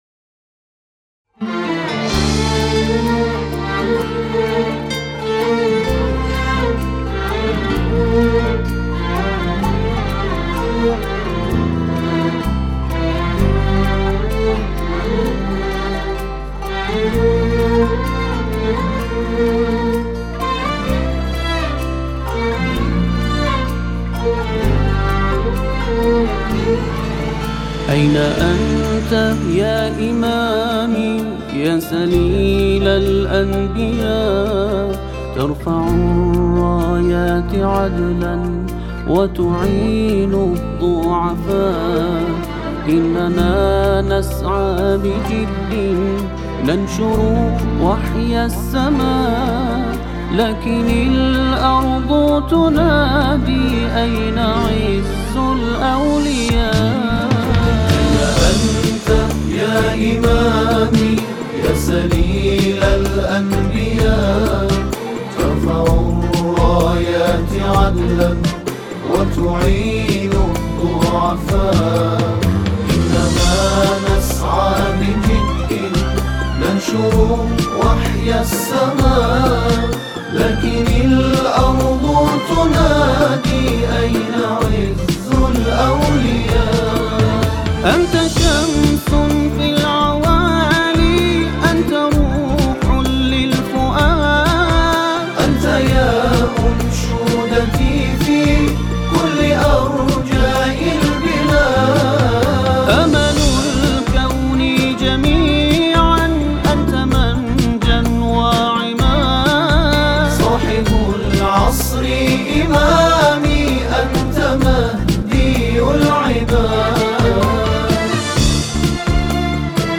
در این اثر شاهد اجرای عربی و فارسی هستیم
نوازنده ارکستر زهی
نوازنده قانون
نوازنده گیتار